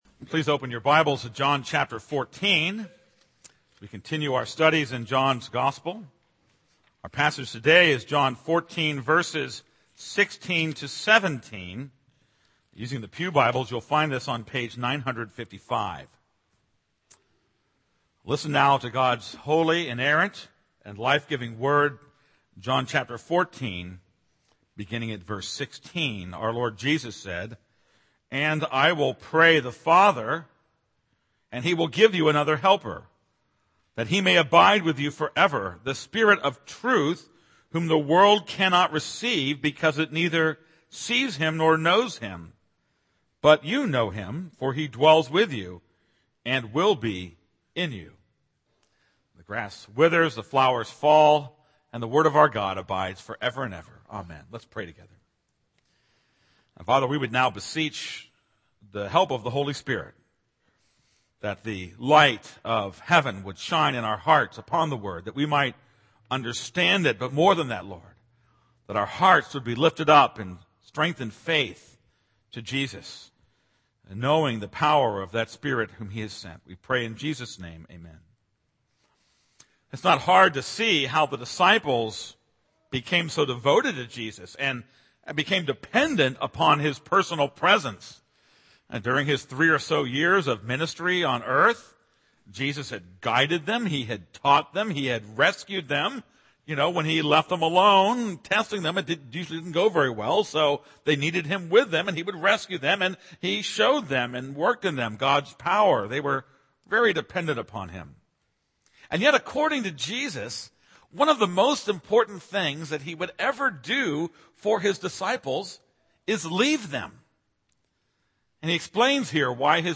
This is a sermon on John 14:16-17.